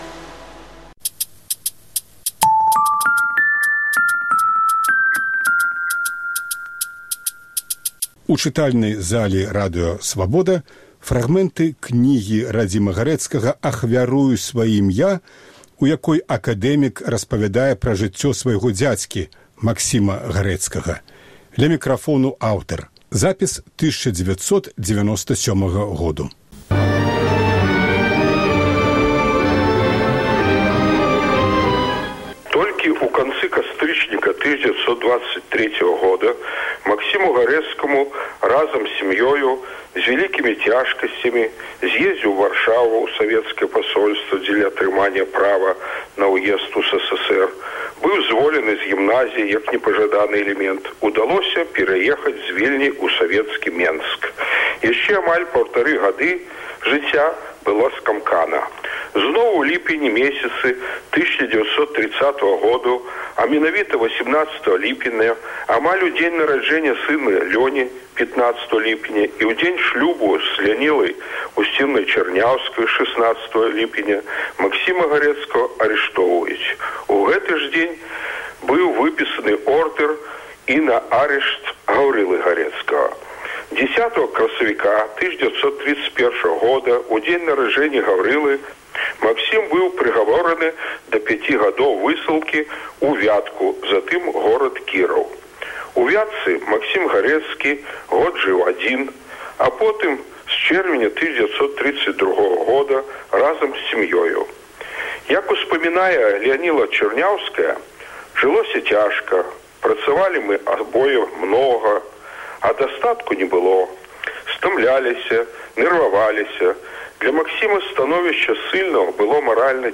Акадэмік Радзім Гарэцкі чытае фрагмэнты ўспамінаў пра свайго дзядзьку, пісьменьніка Максіма Гарэцкага.